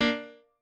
piano8_6.ogg